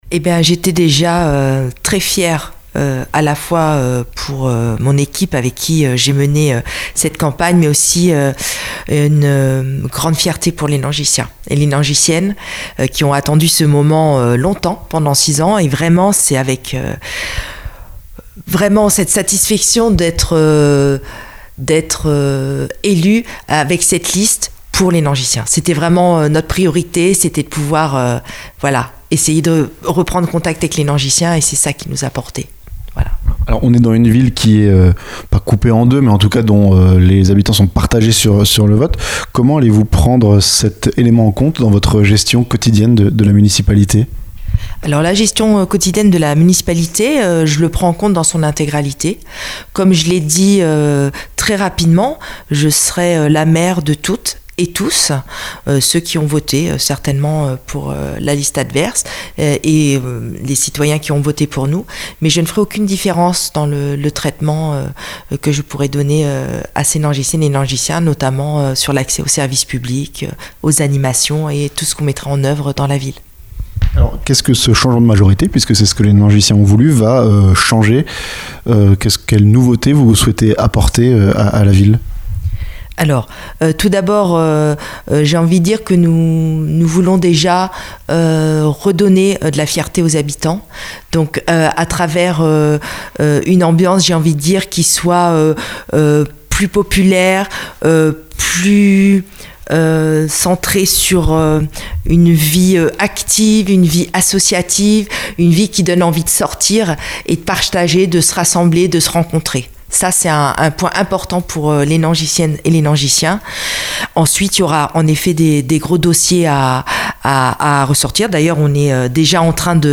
NANGIS - Entretien avec Clotilde Lagoutte, nouvelle maire